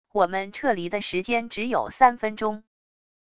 我们撤离的时间只有三分钟wav音频生成系统WAV Audio Player